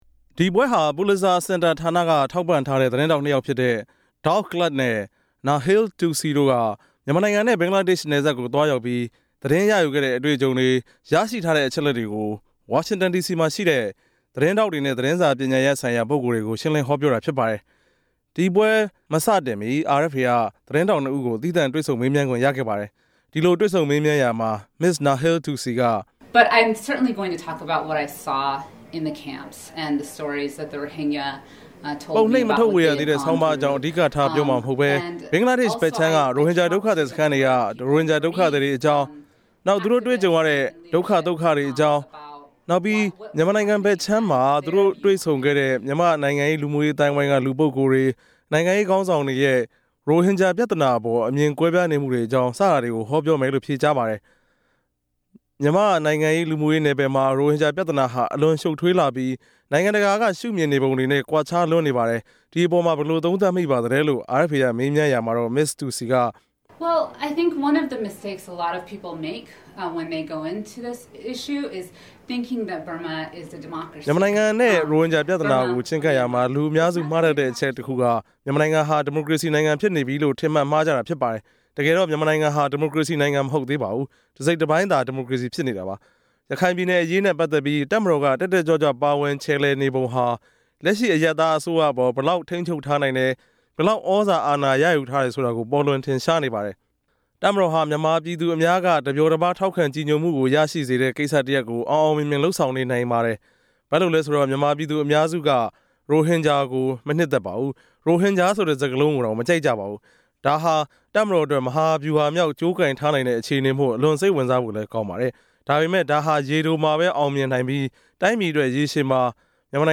“မြန်မာနိုင်ငံနဲ့ ရိုဟင်ဂျာအရေး”လို့အမည်ပေးထားတဲ့ ဆွေးနွေးပွဲတခုကို အမေရိကန်ပြည်ထောင်စု ဝါရှင်တန်ဒီစီမြို့တော်မှာရှိတဲ့ “ကမ္ဘာ့ပဋိပက္ခများသတင်းရေးသားတင်ပြရေး ပူလစ်ဇာစင်တာ”မှာ ကျင်းပခဲ့ပါတယ်။